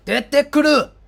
Sound effect
罕见语音1.wav